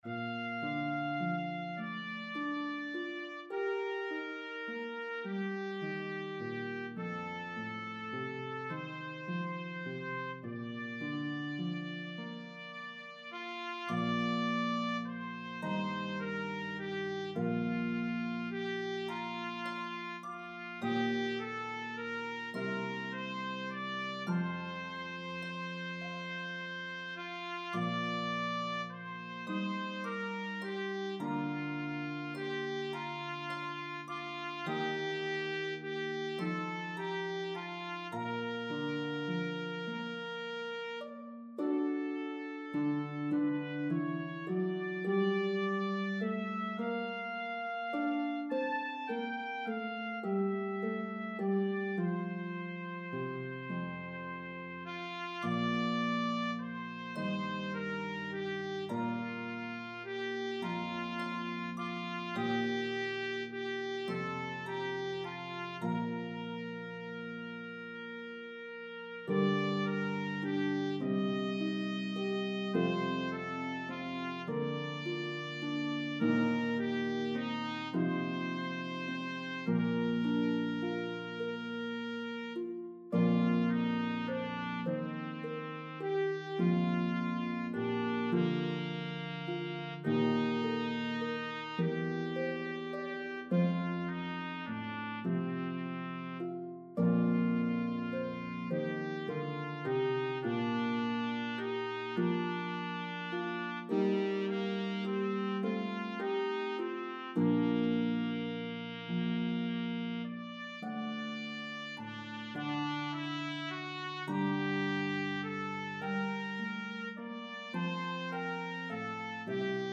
The melody is divided evenly between parts.